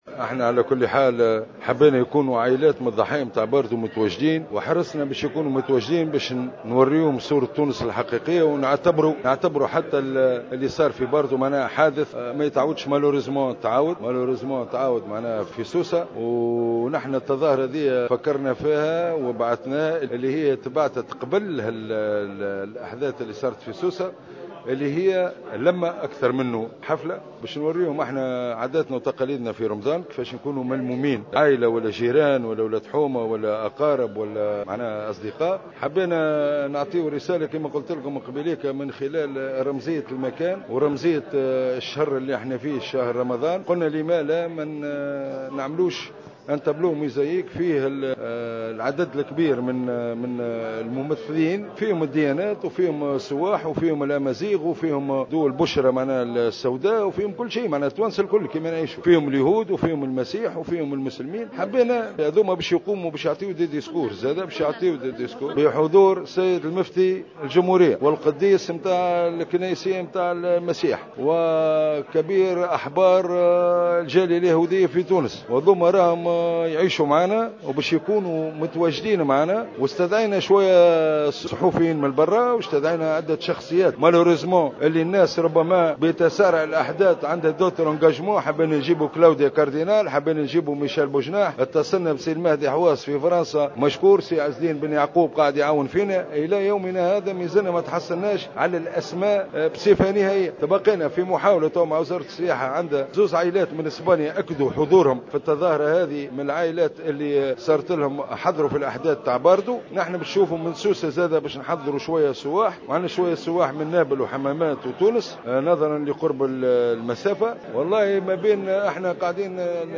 على هامش ندوة صحفية عقدت بالخصوص